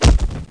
bodfall1.mp3